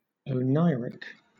Ääntäminen
Southern England
UK : IPA : /əʊˈnaɪ.ɹɪk/